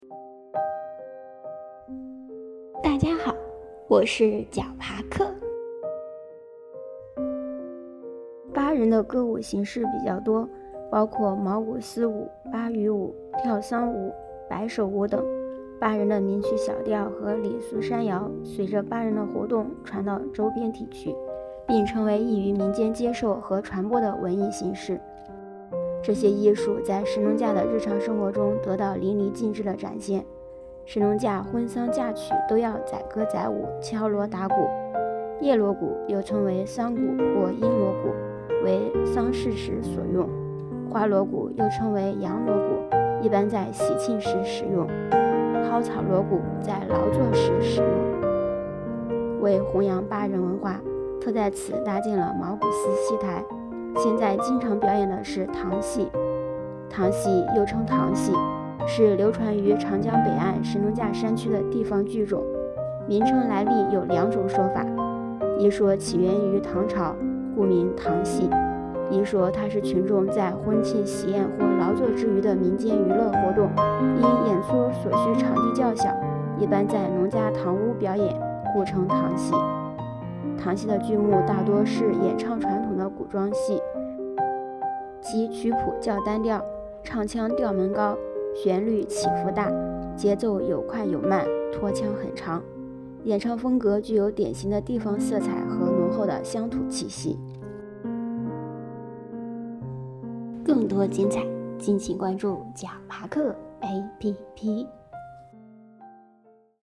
“茅古斯”戏台----- 豌豆荚 解说词: 巴人的歌舞形式比较多，包括茅古斯舞、巴渝舞、跳丧舞、摆手舞等，巴人的民曲小调和俚俗山谣随着巴人的活动传到周边地区，并成为易于民间接受和传播的文艺形式。